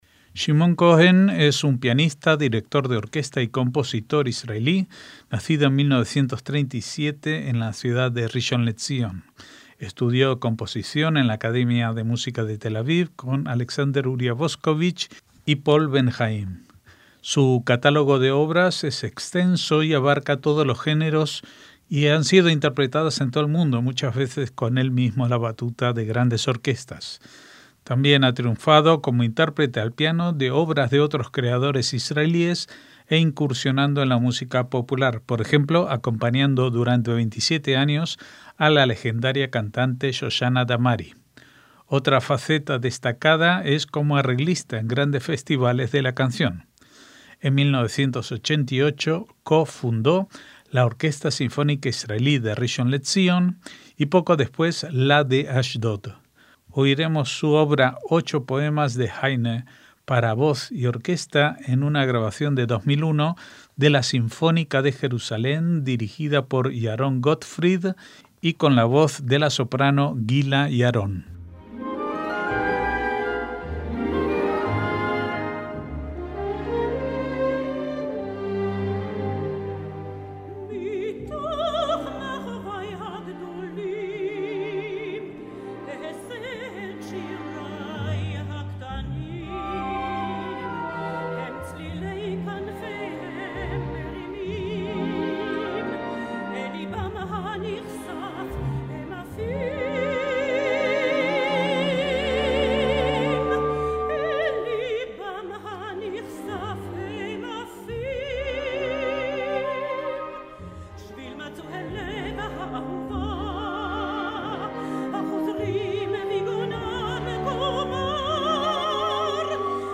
MÚSICA CLÁSICA